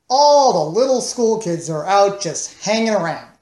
Tags: Comedy